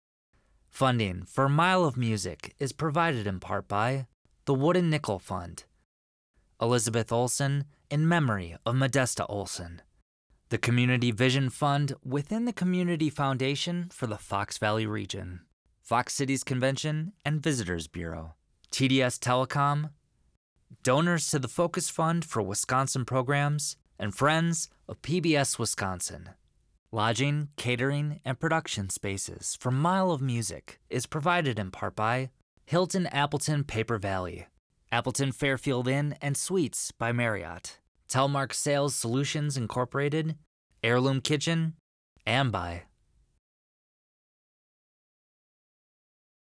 FUNDERS_END_V2_DIALOG.wav